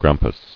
[gram·pus]